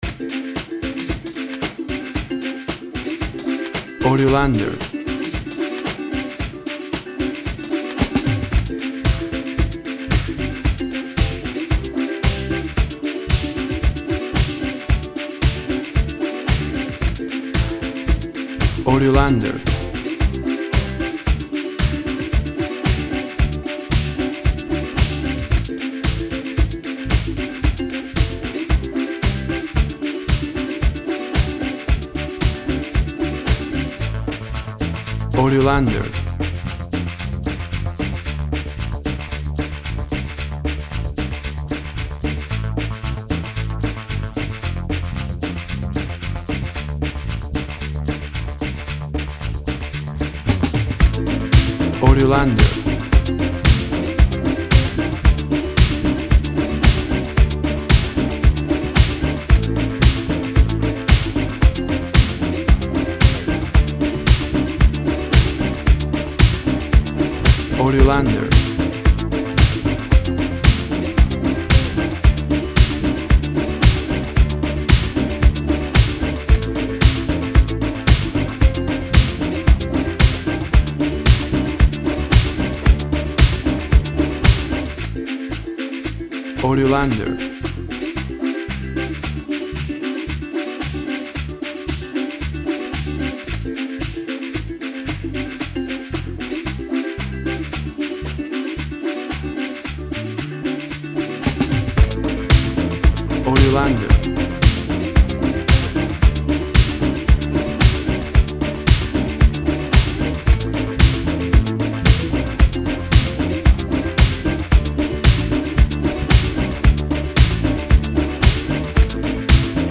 Latin Fusion Music.
Tempo (BPM) 112